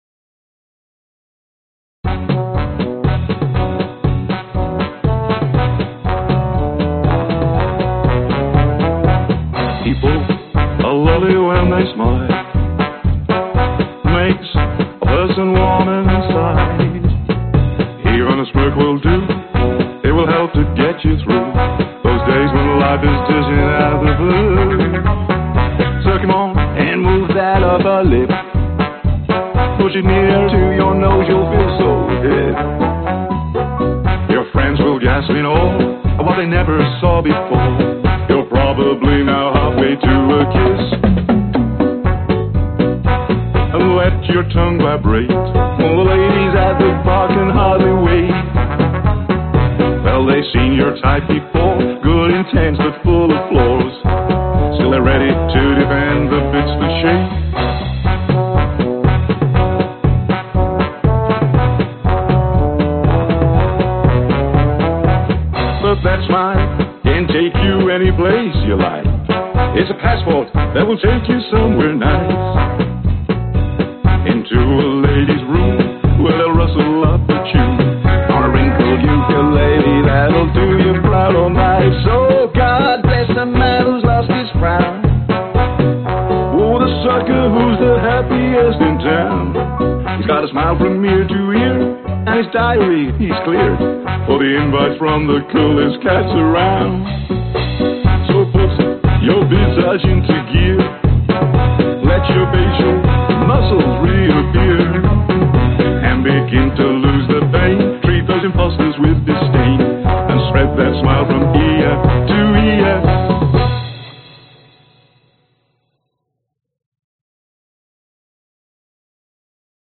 描述：摆动的速度有点快。Fis小调，H小调，...
Tag: 摇摆 人声 铜管 骨头 钢琴